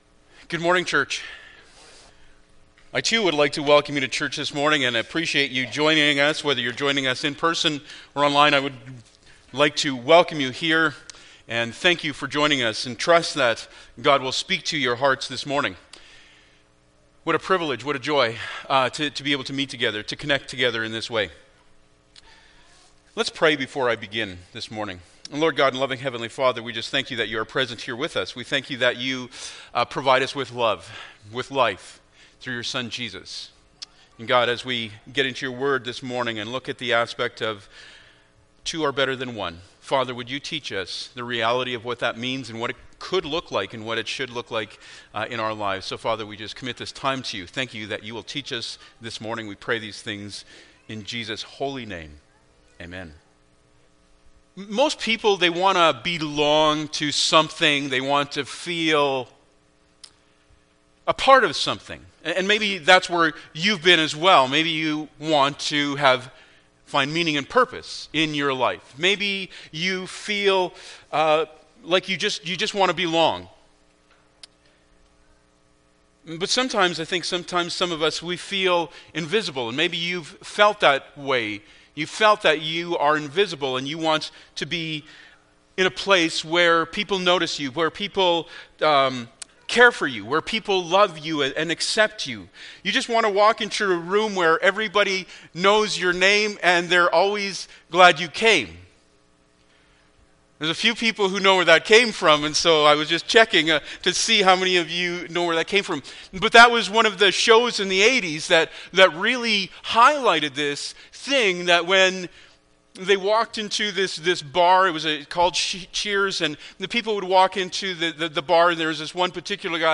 Ecclesiastes 4:9-12 Service Type: Sunday Morning Bible Text